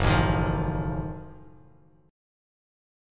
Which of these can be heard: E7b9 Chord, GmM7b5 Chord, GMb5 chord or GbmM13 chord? GbmM13 chord